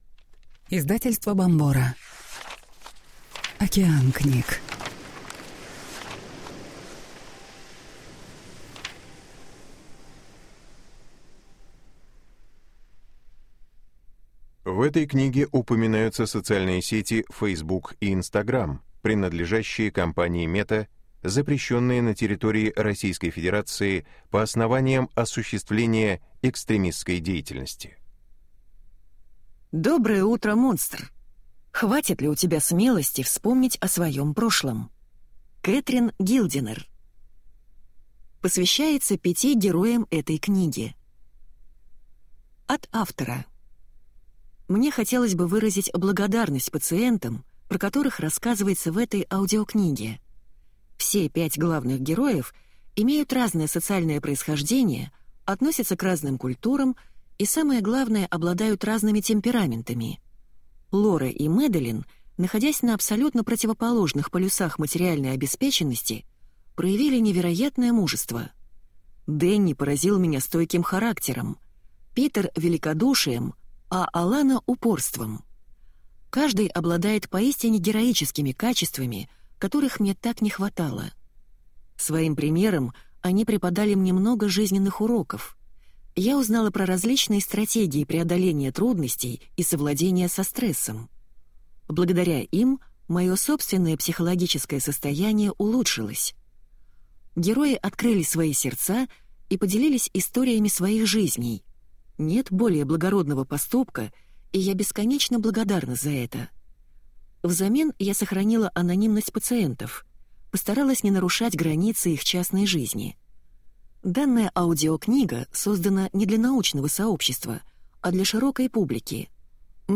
Аудиокнига Доброе утро, монстр! Хватит ли у тебя смелости вспомнить о своем прошлом?